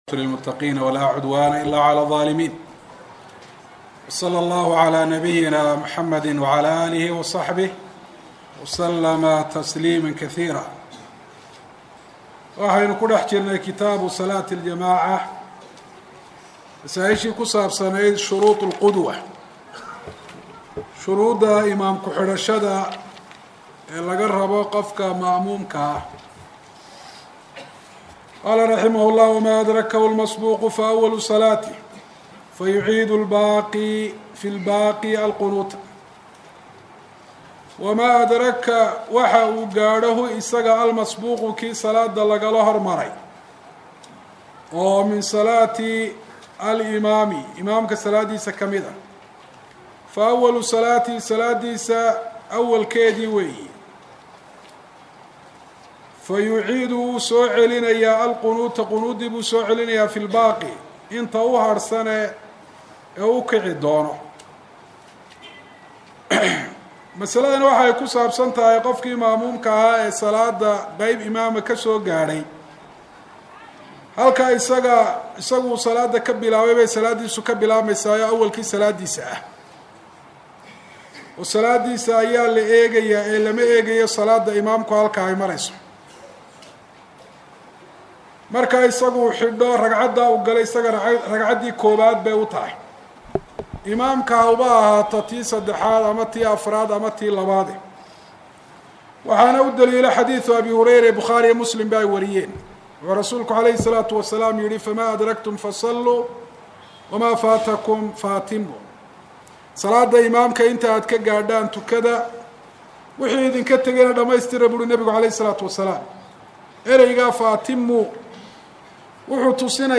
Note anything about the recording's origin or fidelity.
Masjid Nakhiil – Hargaisa